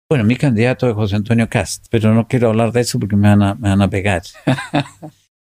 También lo hizo el expresidente de Renovación Nacional, Carlos Larraín, quien —entre risas, advirtiendo que si hablaba más le iban a “pegar”—, reafirmó su respaldo al candidato del Partido Republicano, José Antonio Kast.